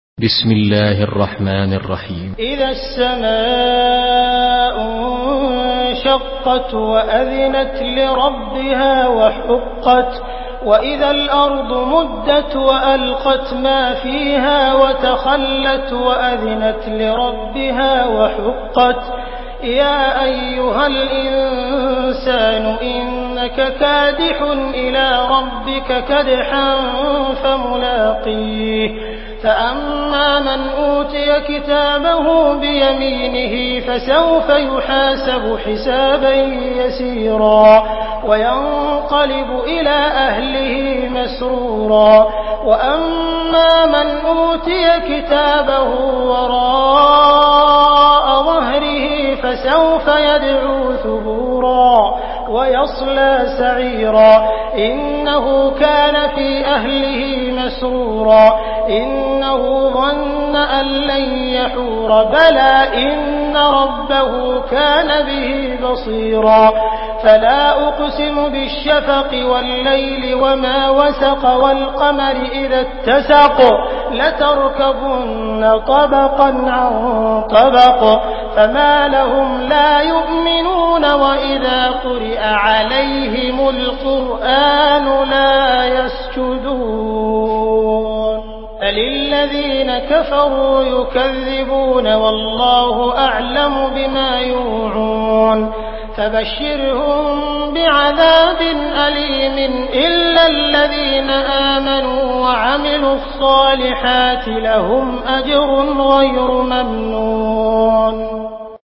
Surah Inşikak MP3 in the Voice of Abdul Rahman Al Sudais in Hafs Narration
Murattal Hafs An Asim